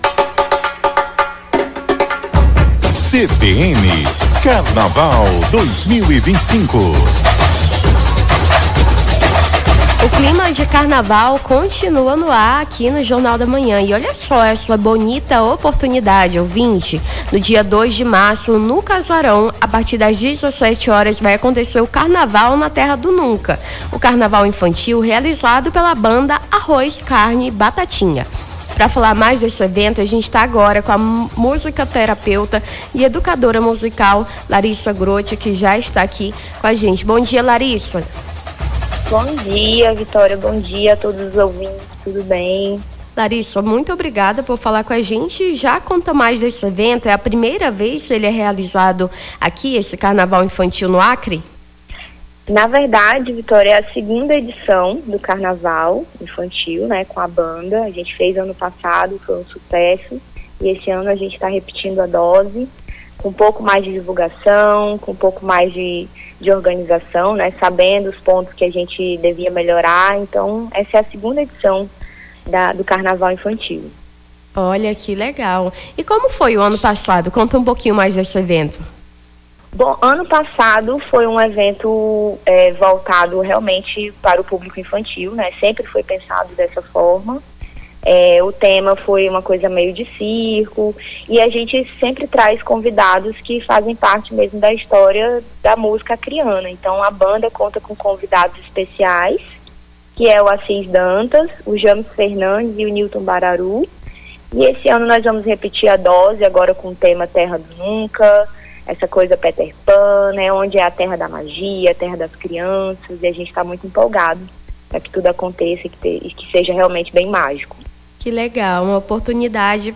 Nome do Artista - CENSURA - ENTREVISTA CARNAVAL INFANTIL (27-02-25).mp3